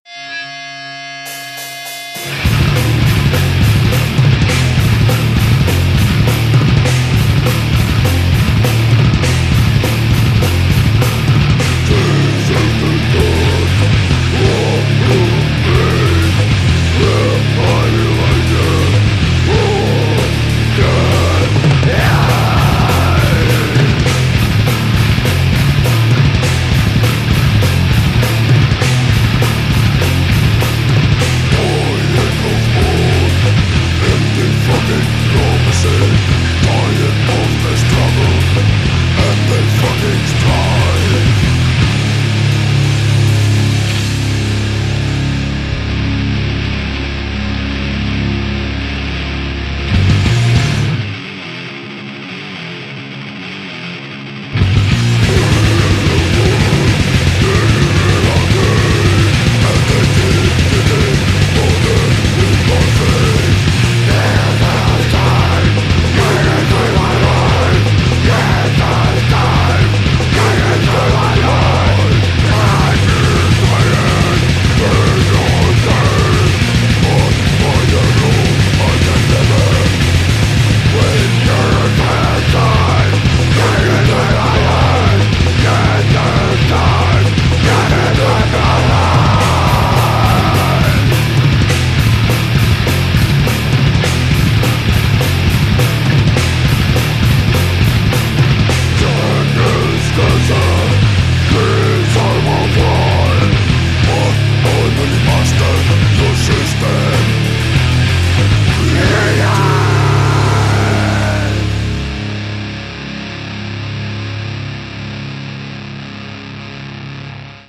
ULEÅBORG HARDCORE